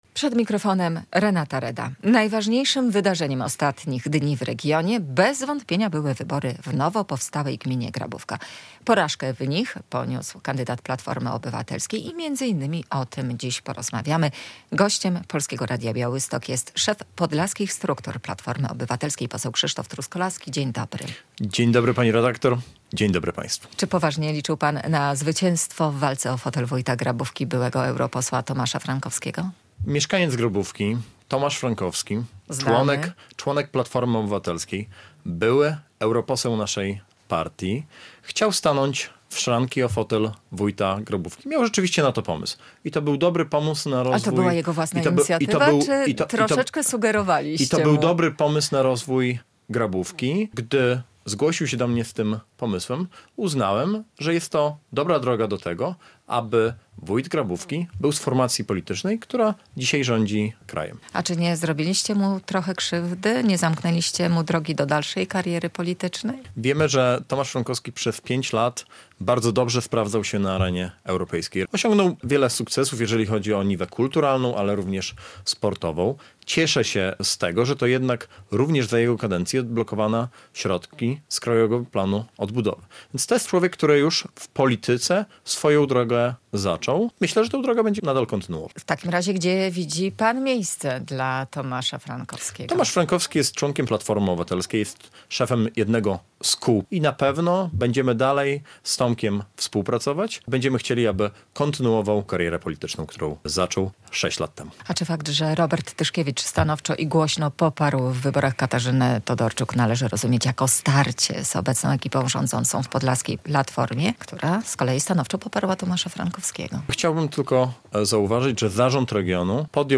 W Rozmowie Dnia w Polskim Radiu Białystok poruszono także temat inwestycji w podlaskie szpitale.